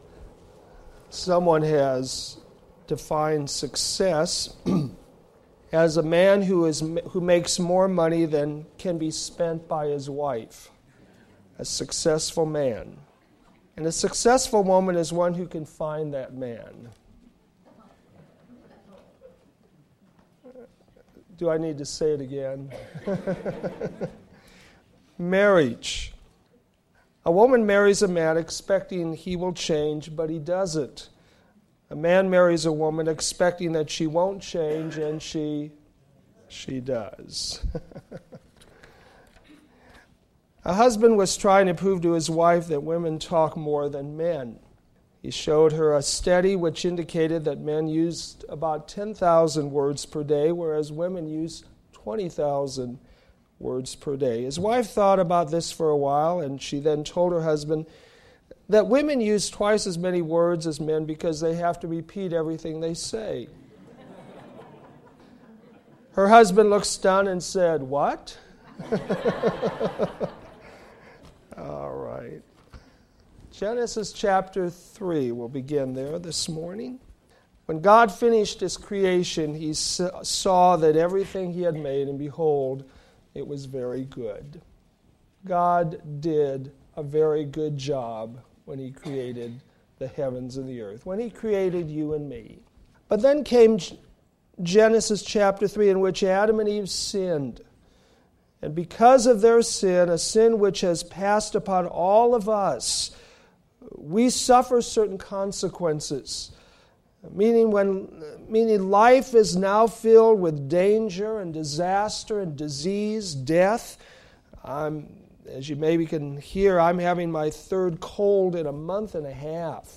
Sermons based on Old Testament Scriptures